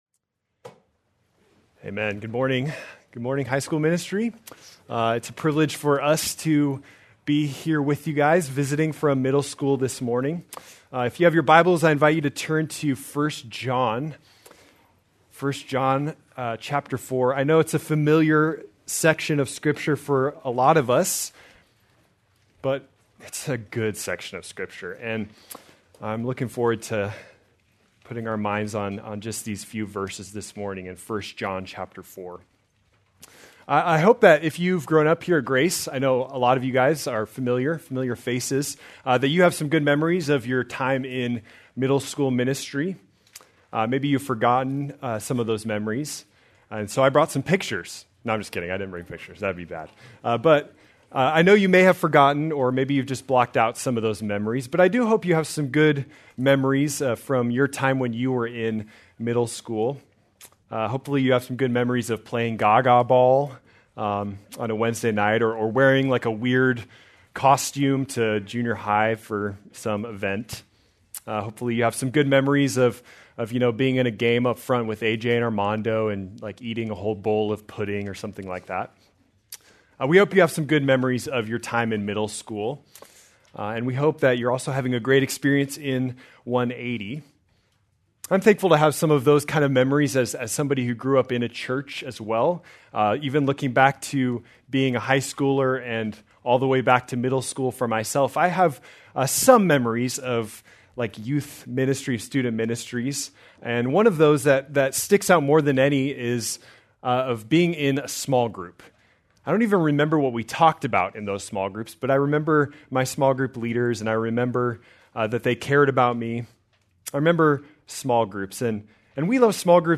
October 19, 2025 - Sermon | 180 Ministry | Grace Community Church